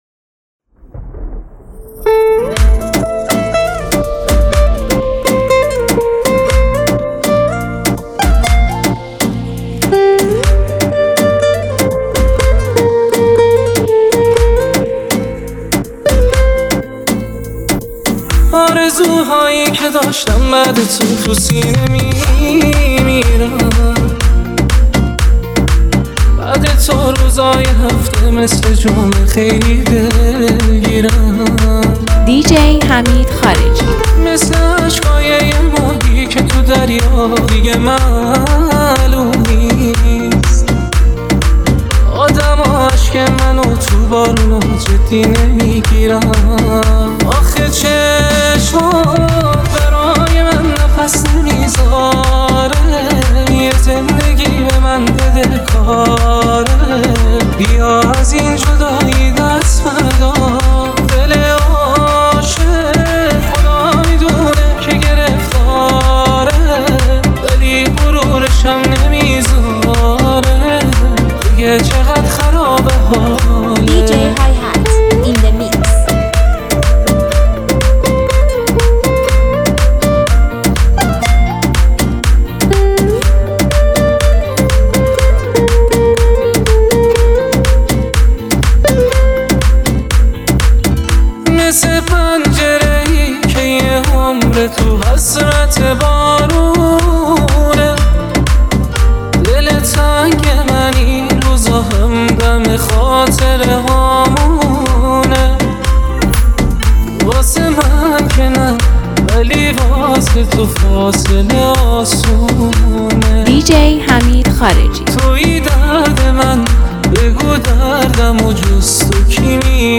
بیس دار MP3heheshmat